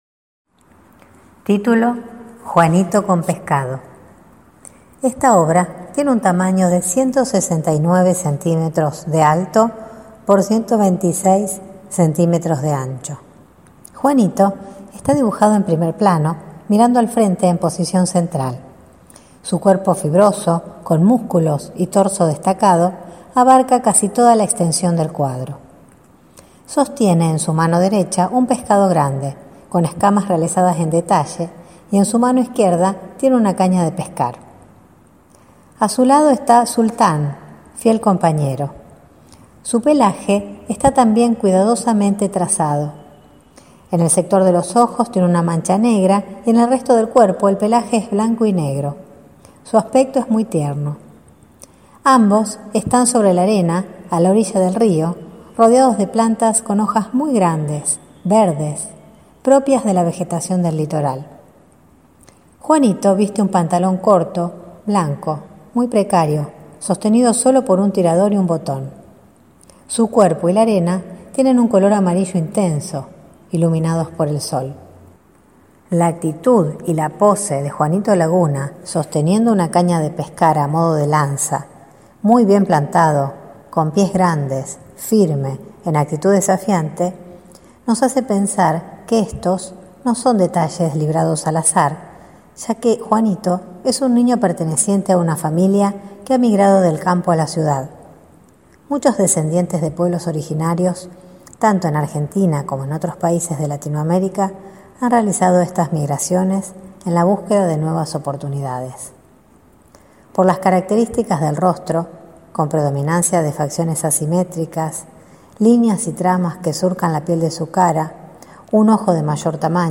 Audiodescripciones y Audioguías